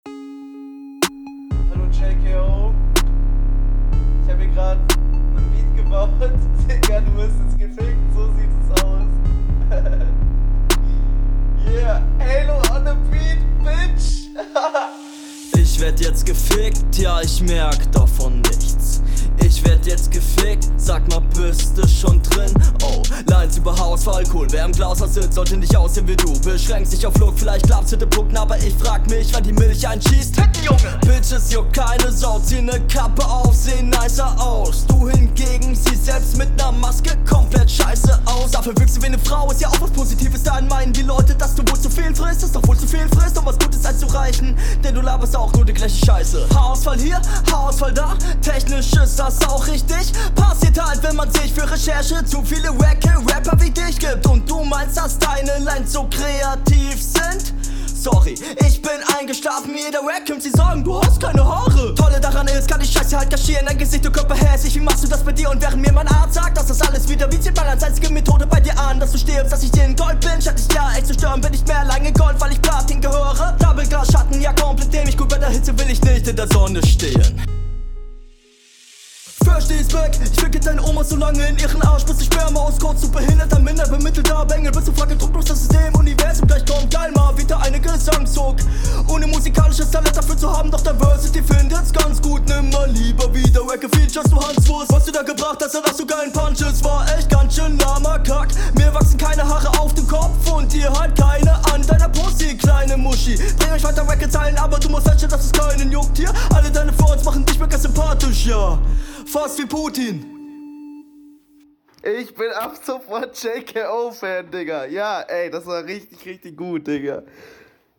Man merkt ein bisschen, dass er den Beat nicht selbst gepickt hat, aber er kommt …